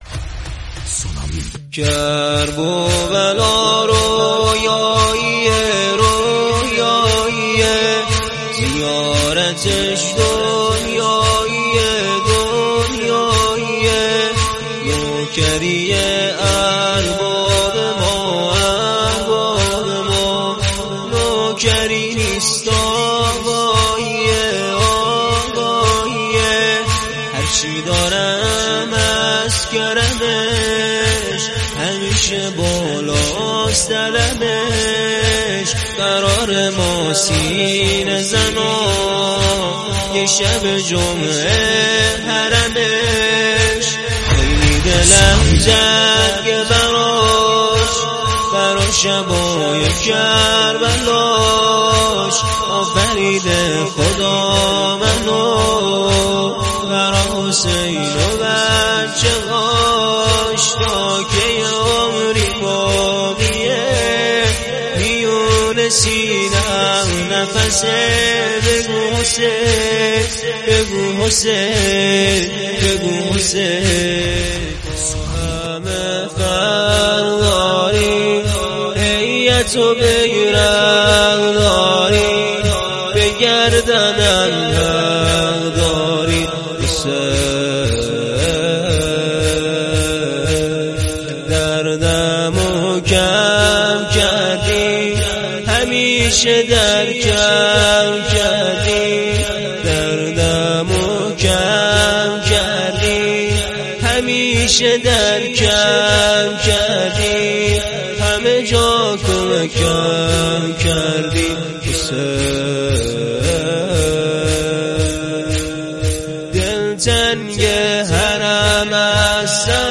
میکس موزیک مداحی